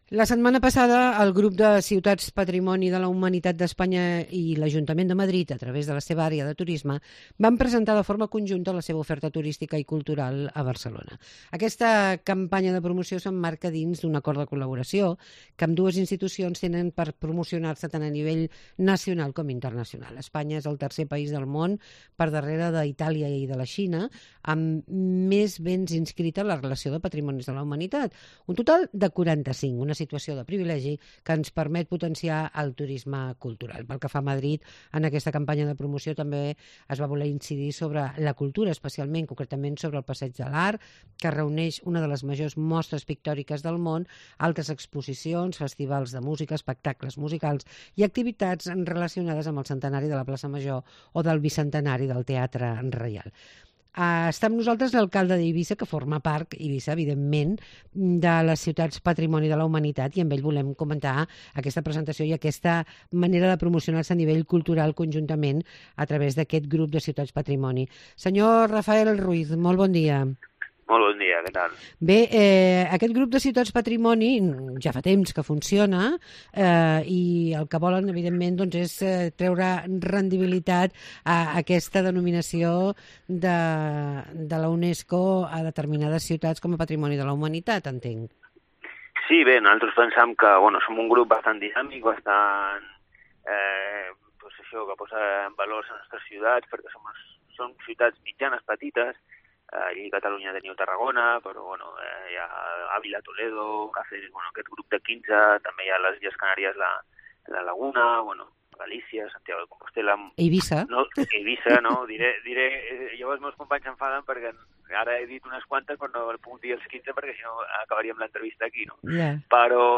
Entrevista al alcalde de Ibiza, Rafal Ruiz